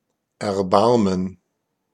Ääntäminen
Synonyymit commisération compatissance Ääntäminen France: IPA: [kɔ̃.pa.sjɔ̃] Haettu sana löytyi näillä lähdekielillä: ranska Käännös Ääninäyte Substantiivit 1.